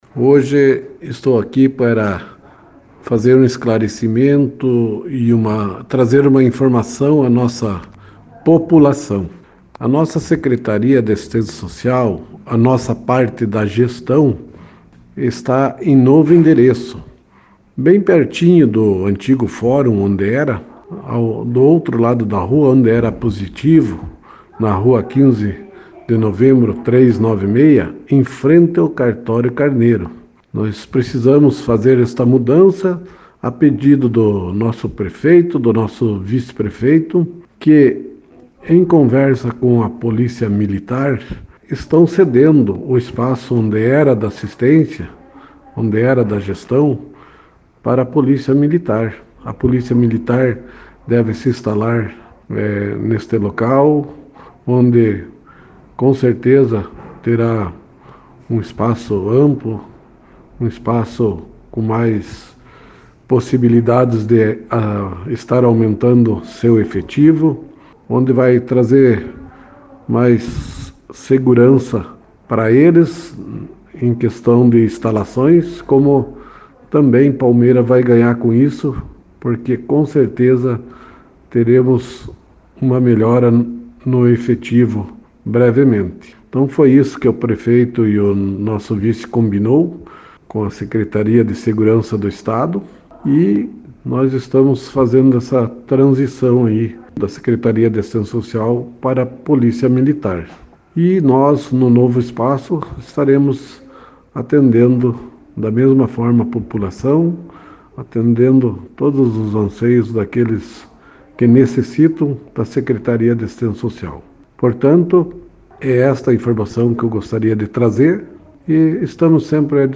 Secretário de Assistência Social fala sobre alteração de endereço